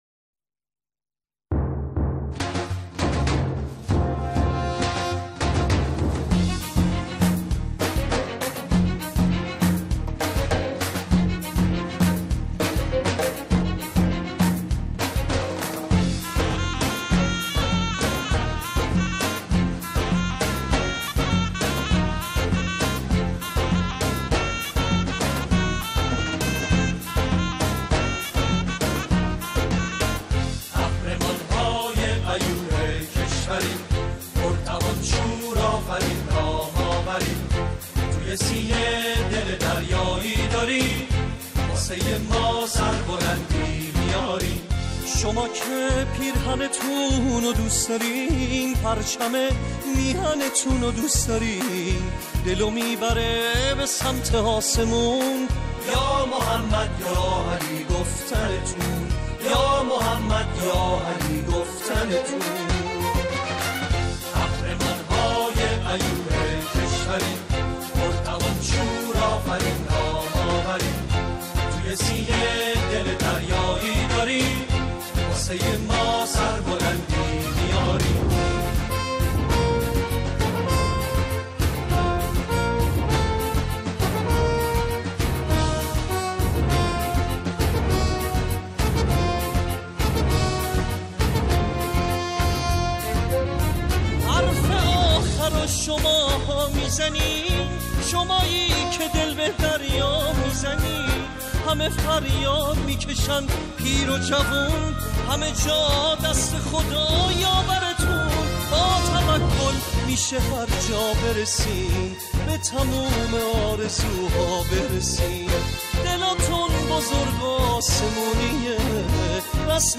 سرودهای ورزشی